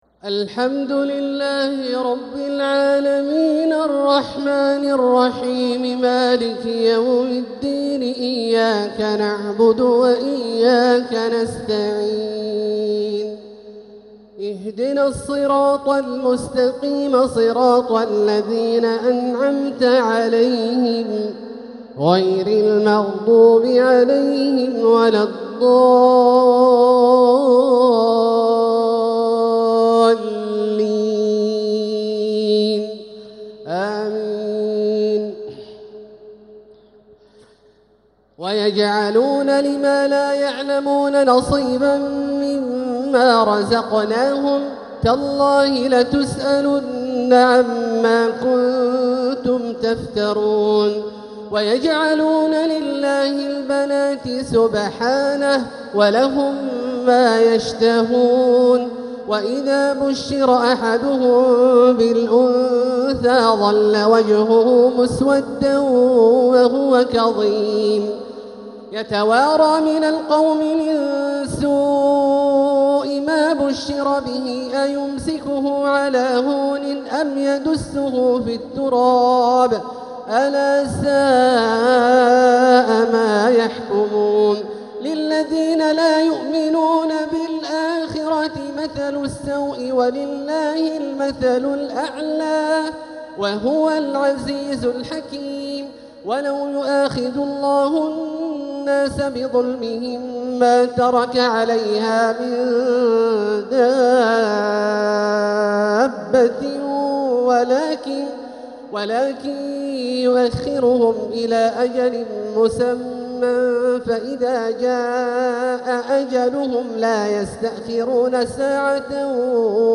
تراويح ليلة 19 رمضان 1446هـ من سورة النحل (56-103) | taraweeh 19th night Ramadan 1446H Surah An-Nahl > تراويح الحرم المكي عام 1446 🕋 > التراويح - تلاوات الحرمين